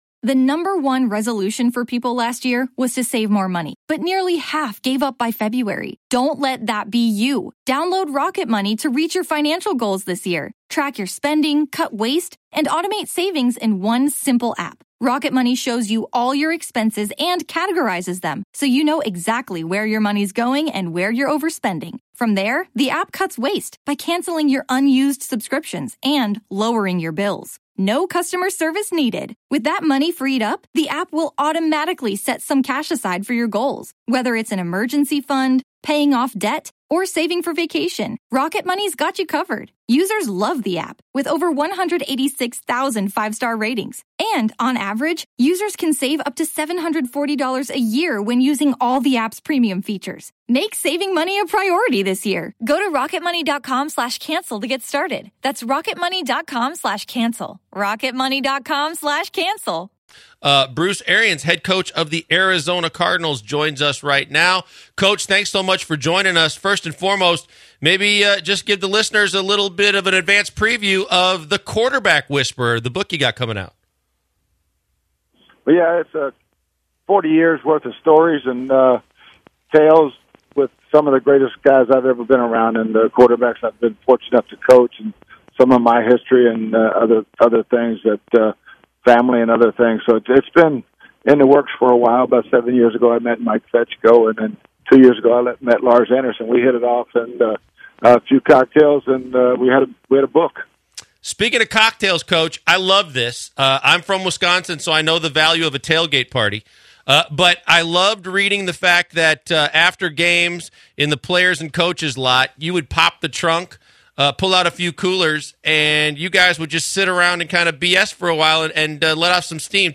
07/10/2017 Bruce Arians Interview
Arizona Cardinals coach Bruce Arians joins The Usual Suspects. He discusses Peyton Manning, Deshaun Watson and being held up at gunpoint.